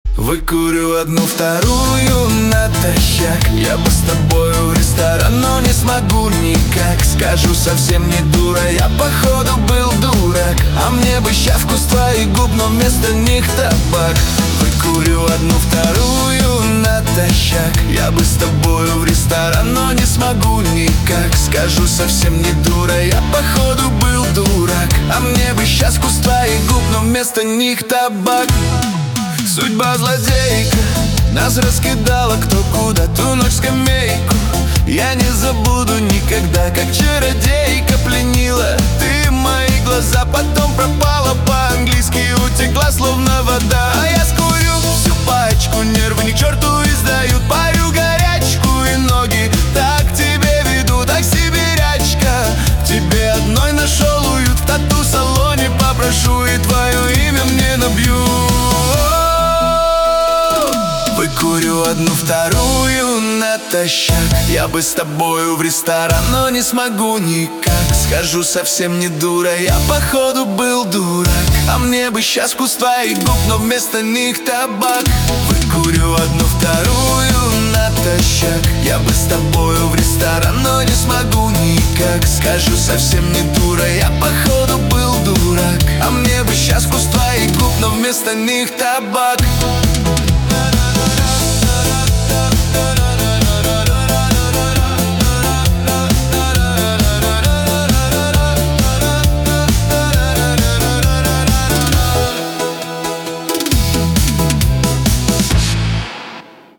диско
грусть
Шансон